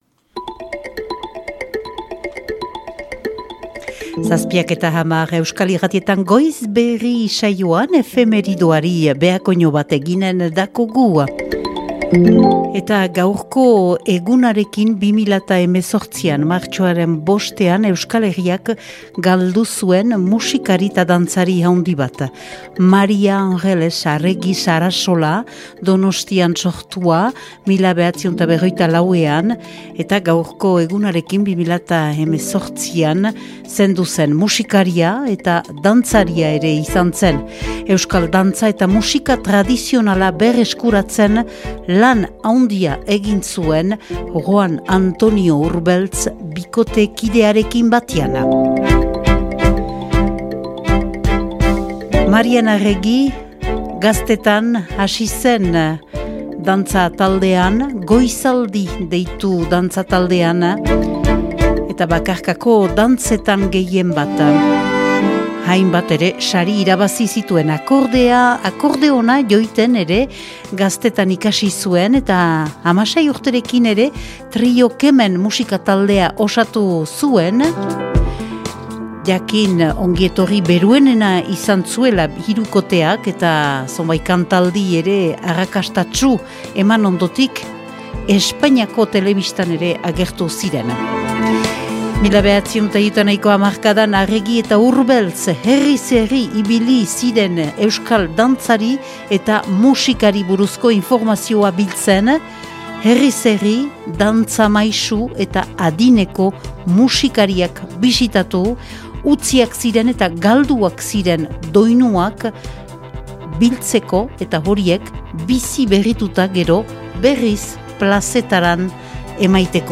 Efemeridea
Euskal Irratietan egindako efemeridea